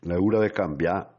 10 frasi in bergamasco sull’annata dell’Atalanta